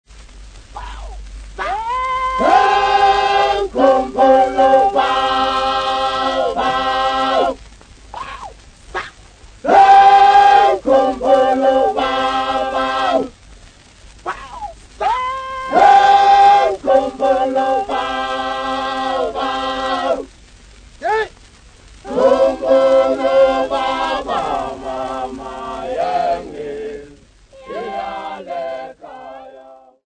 Swazi Men
Popular music--Africa
Field recordings
sound recording-musical
Mbube style topical song